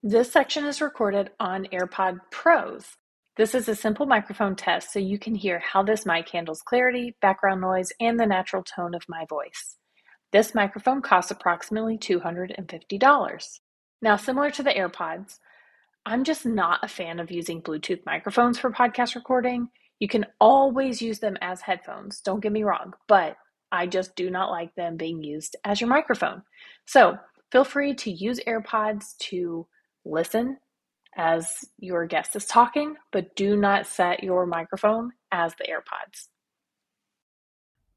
Listen to the Apple Airpods